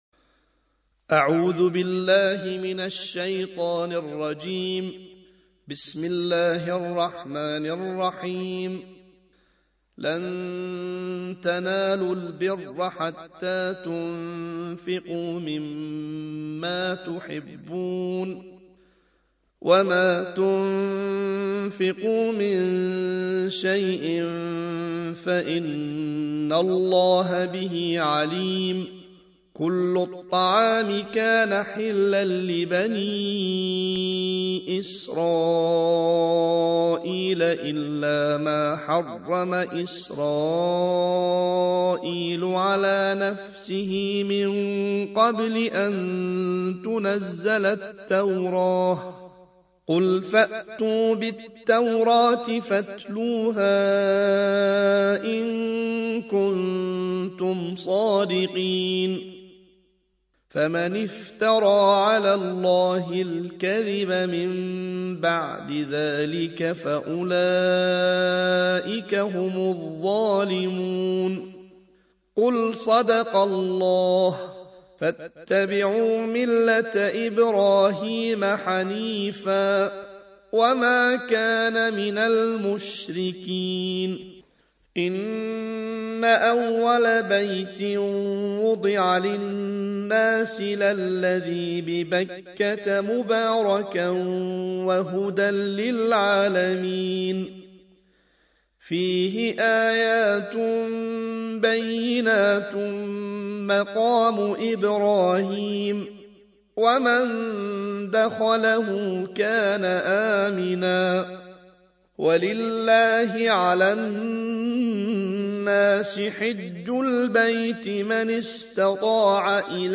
الجزء الرابع / القارئ